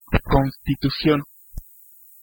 Ääntäminen
IPA: [fɛɐ̯.ˈfa.sʊŋ]